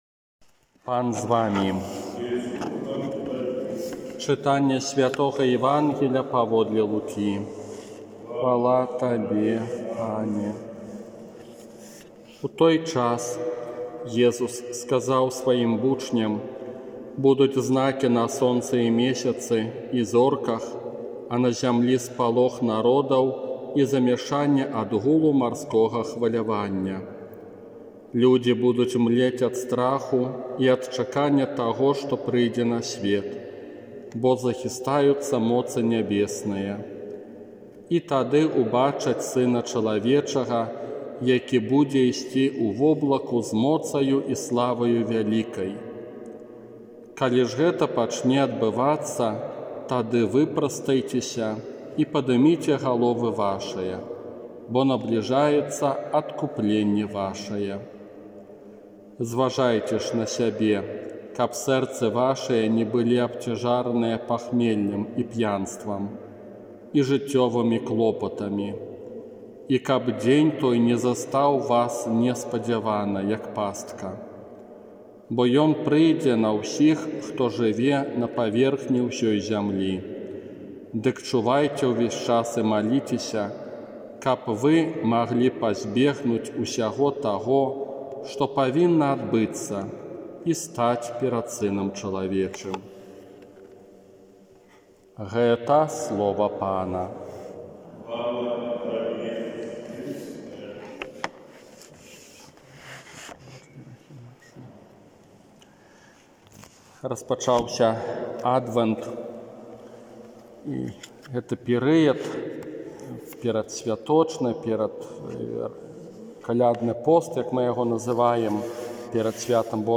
ОРША - ПАРАФІЯ СВЯТОГА ЯЗЭПА
Казанне на першую нядзелю Адвэнта